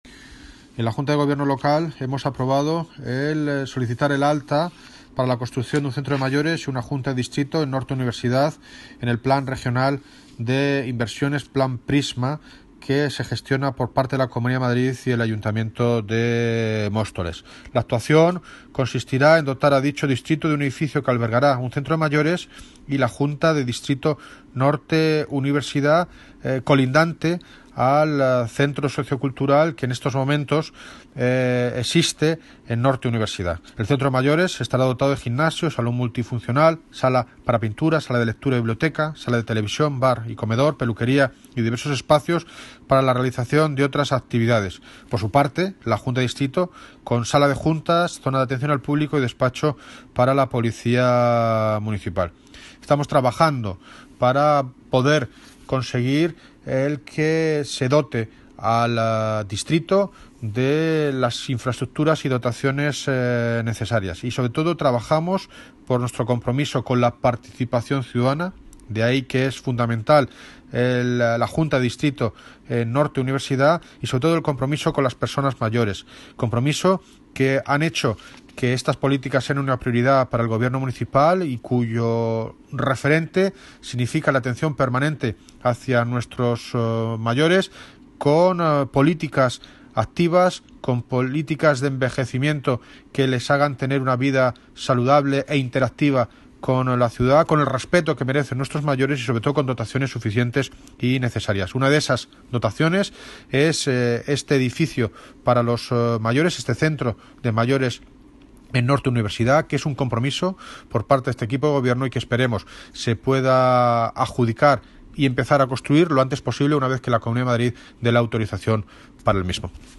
Audio - David Lucas (Alcalde de Móstoles) Sobre Centro de mayores y Junta en Distrito Norte Universidad
Audio - David Lucas (Alcalde de Móstoles) Sobre Centro de mayores y Junta en Distrito Norte Universidad.mp3